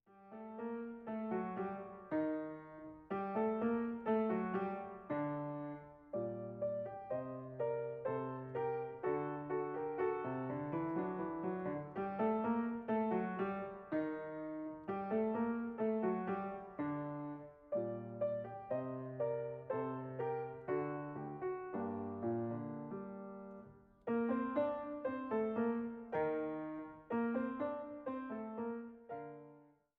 Mezzosopran, Orgel
Bariton, Orgel, Cembalo
Laute
Violoncello, Viola da gamba